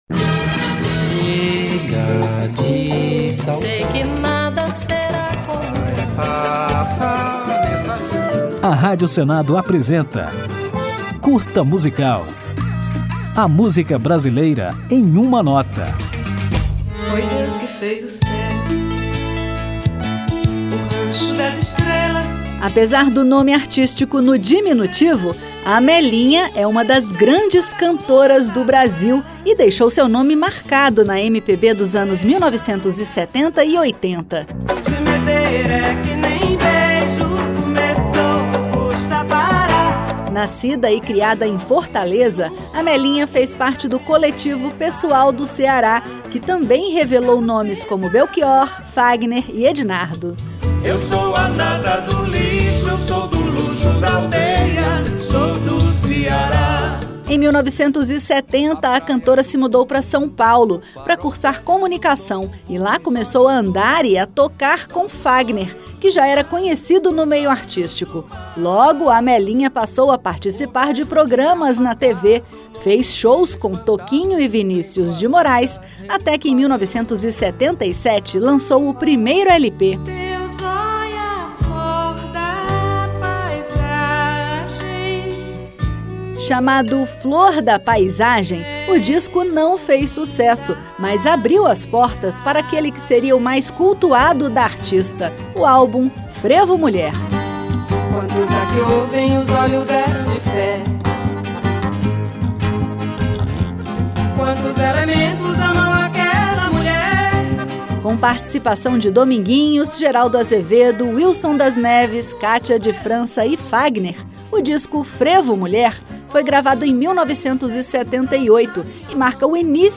Depois de conhecer um pouco da história da cantora, vamos ouvi-la na música Frevo Mulher, que dá nome ao disco mais cultuado de Amelinha.